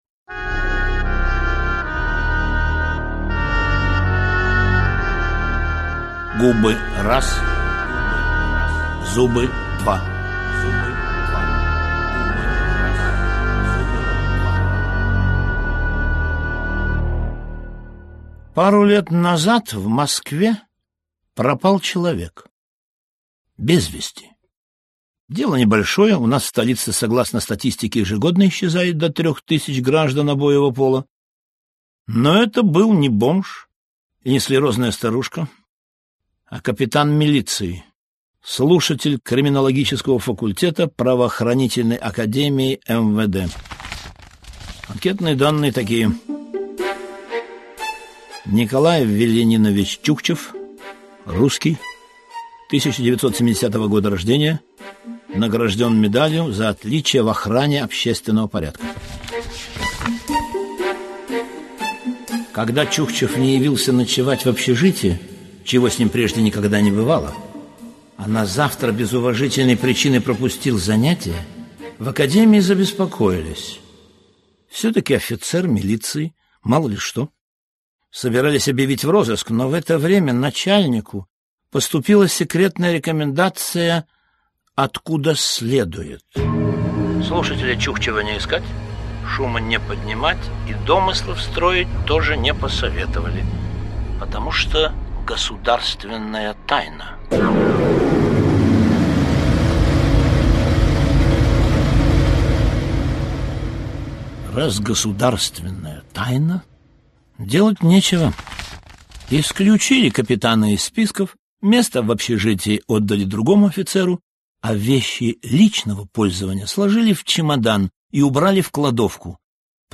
Аудиокнига Старое Донское кладбище (Москва) | Библиотека аудиокниг